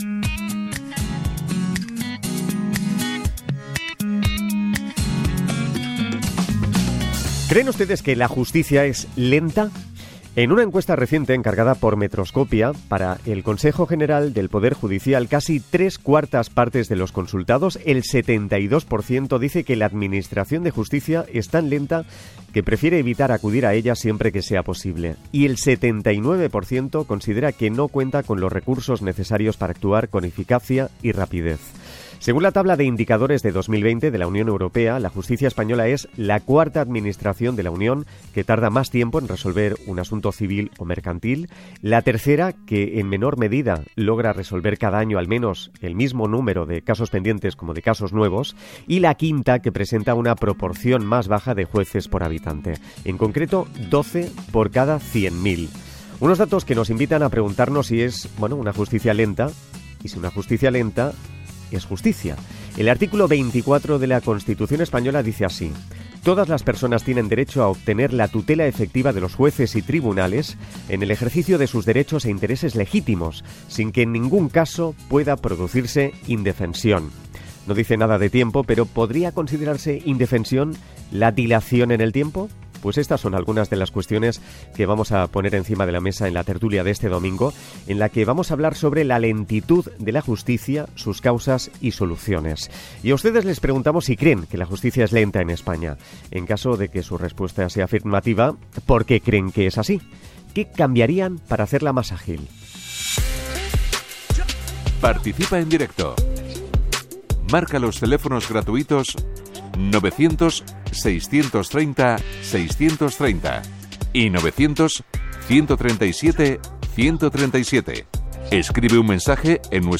CORTE-TERTULIA-LA-JUSTICIA-ES-LENTA-RNE-NO-ES-UN-D-A-CUALQUIERA-RNE.mp3